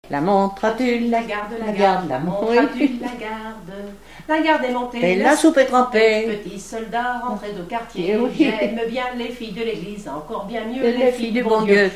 Mémoires et Patrimoines vivants - RaddO est une base de données d'archives iconographiques et sonores.
Rondes à baisers et à mariages fictifs
Pièce musicale inédite